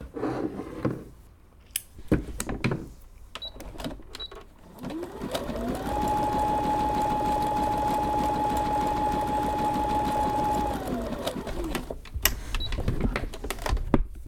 sewing.ogg